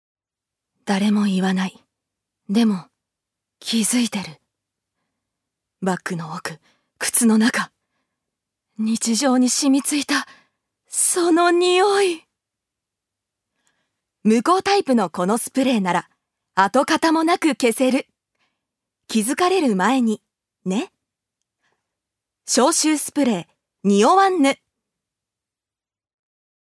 ジュニア：女性
ナレーション３